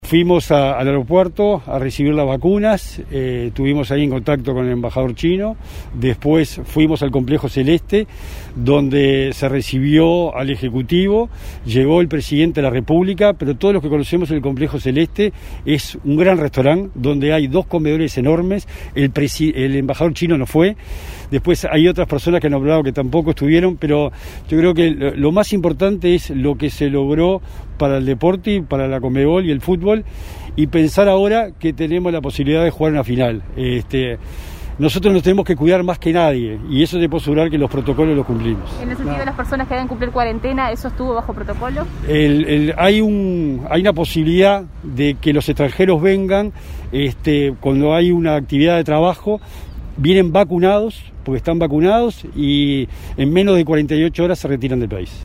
Bauzá explicó en rueda de prensa: “Fuimos al Aeropuerto a recibir las vacunas, tuvimos en contacto con el embajador chino, luego fuimos al Complejo Celeste donde se recibió al Poder Ejecutivo junto al presidente de la República”.
Escuche a Bauzá aquí: